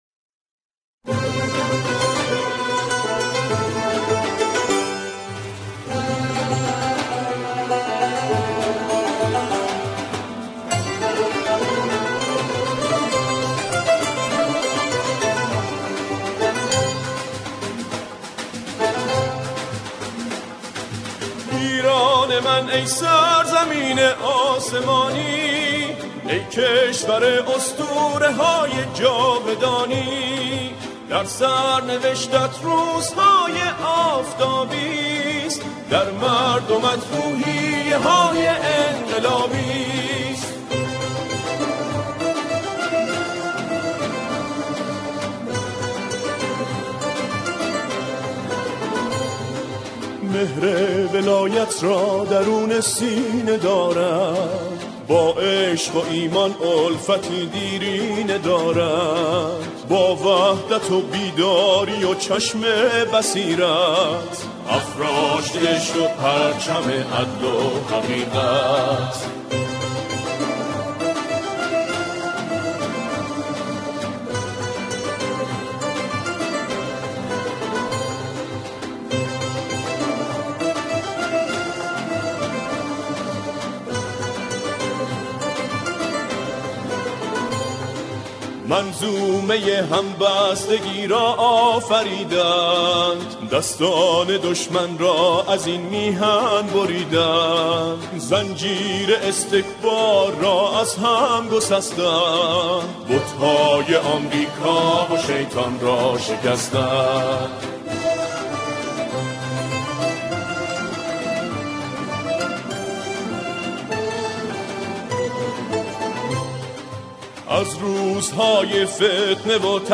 همخوانی شعری به گویش خراسانی
گروهی از جمعخوانان